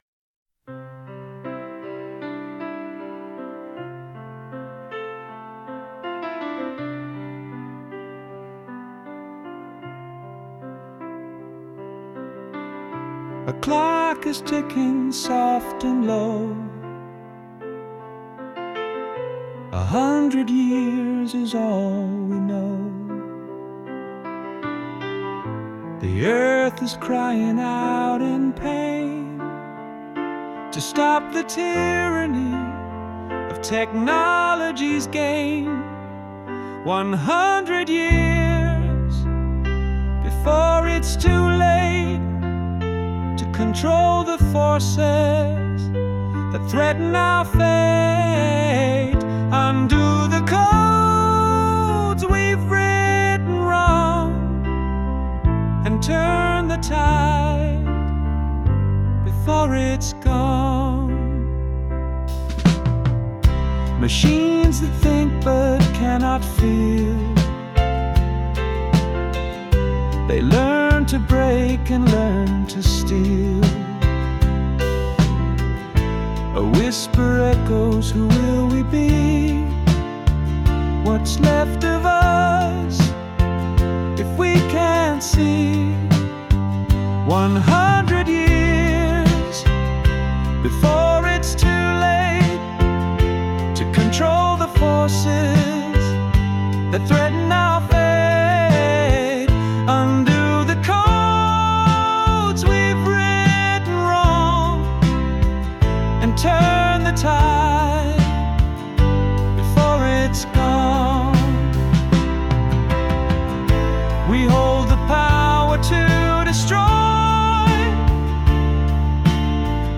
PIANO AND DRUM